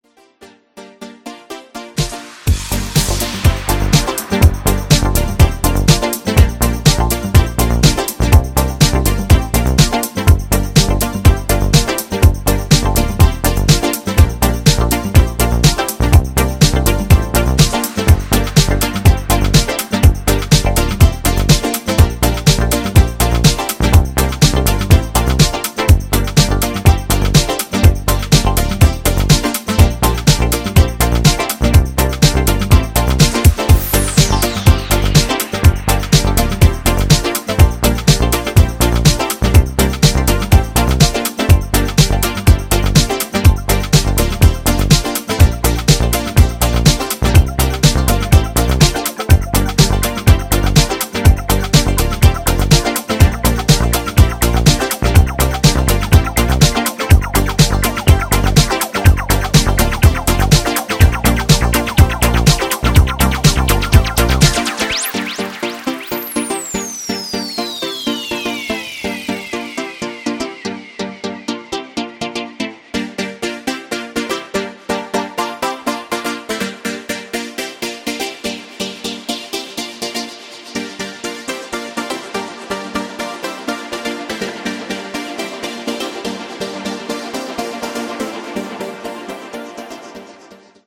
ジャンル(スタイル) NU DISCO / BALEARICA / DEEP HOUSE